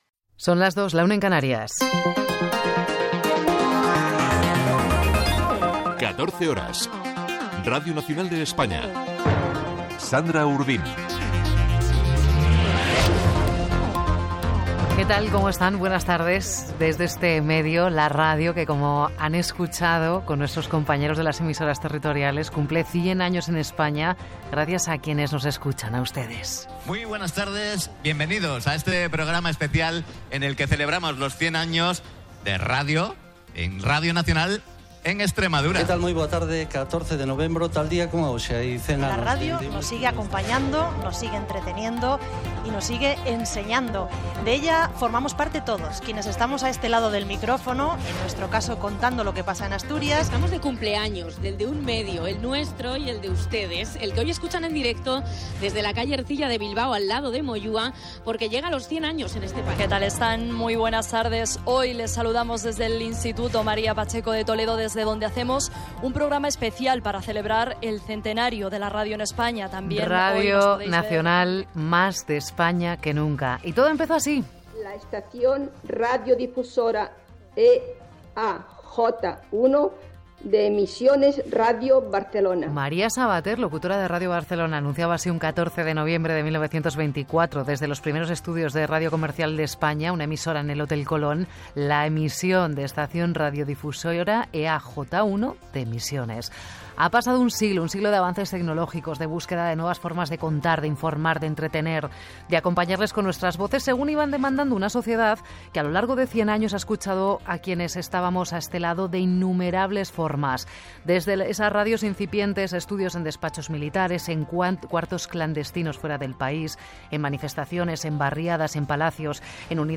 Hora, careta, cent anys de la ràdio a Espanya
Informatiu
FM